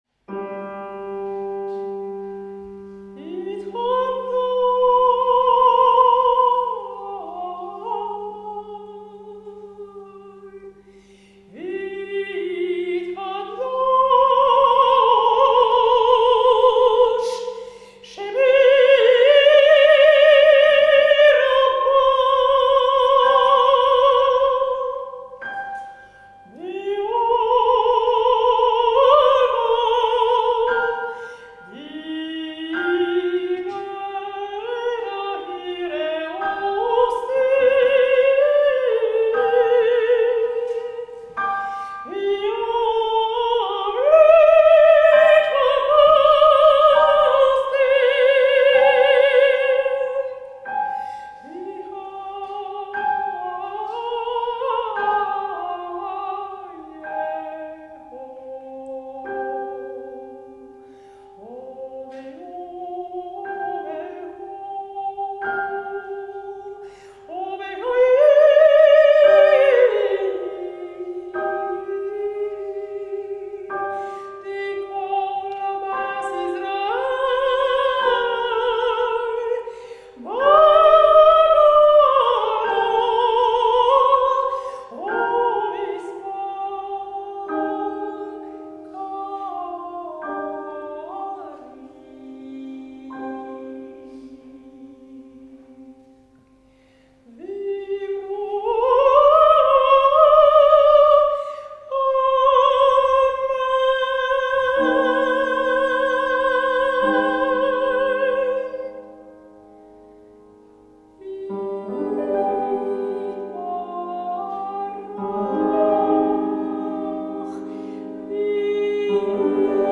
мецо сопрано
пиано